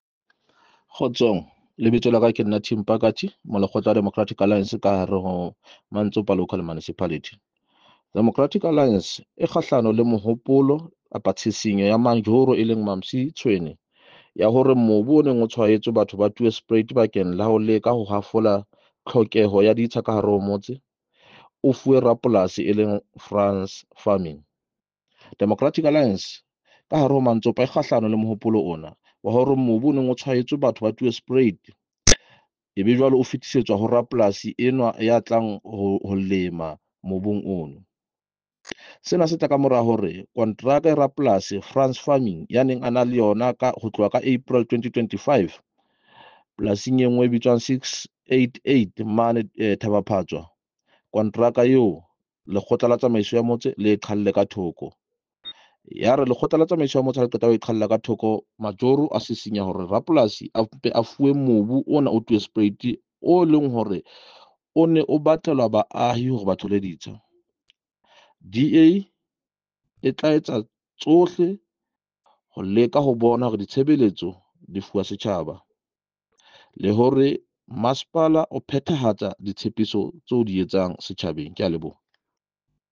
Sesotho soundbites by Cllr Tim Mpakathe and